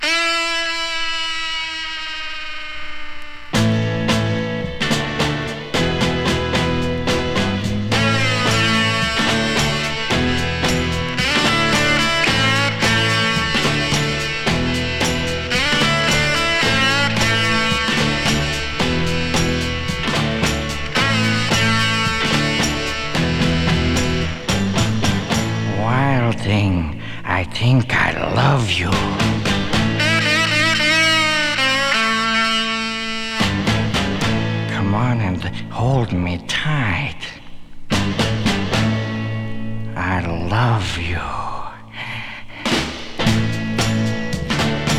Rock'N'Roll, Surf　USA　12inchレコード　33rpm　Stereo